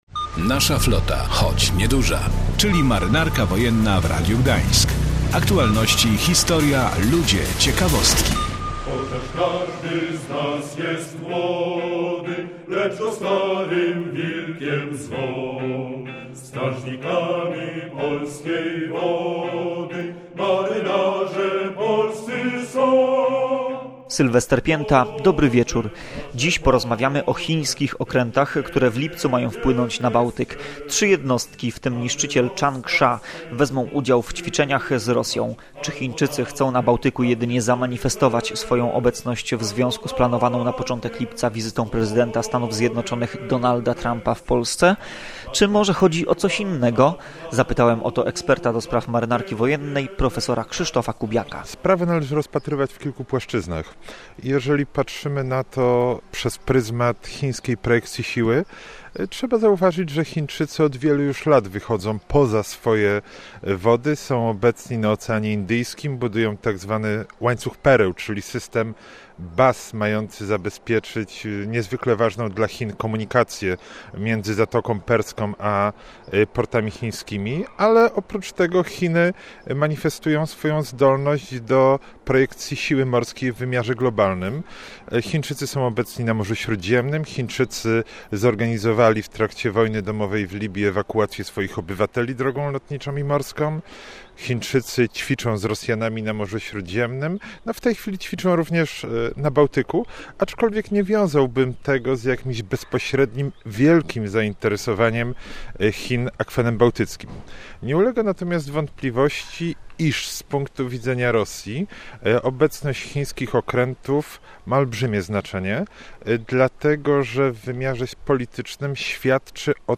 Potem wrócimy do Polski i ruszymy do Portu Wojennego w Gdyni, gdzie w niedzielę odbyło się zwieńczenie Święta Marynarki Wojennej i Dni Morza.
A na koniec kolejny fragment z radiowego archiwum z 2000 roku.